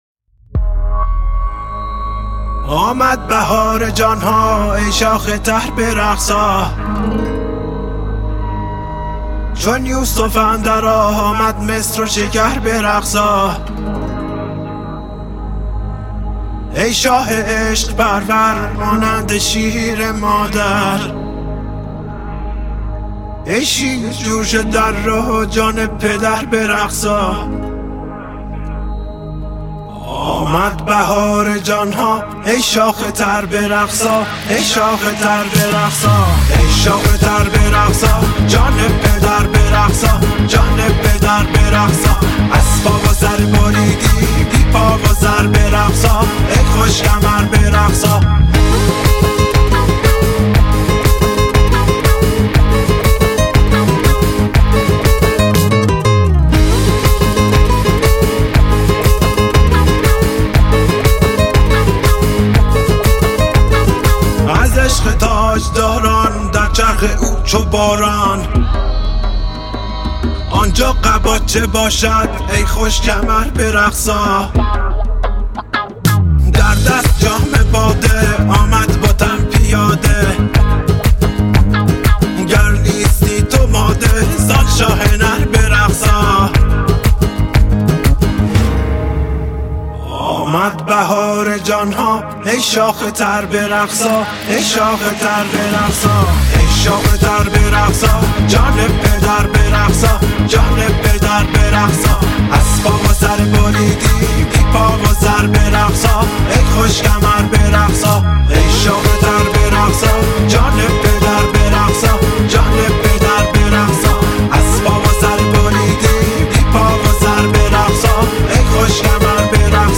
мусиқӣ